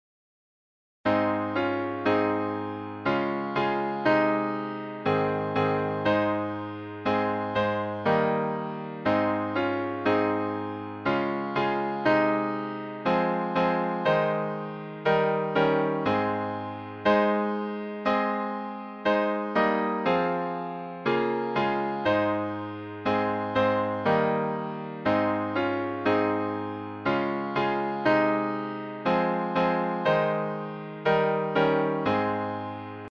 Hymns of praise
Music by: Early USA melody;